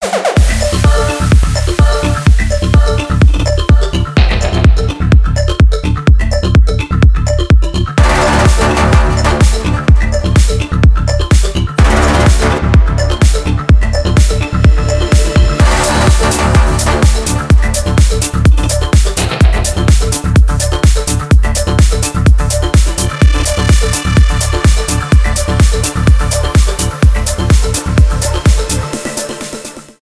без слов
энергичные , клубные , техно
melodic techno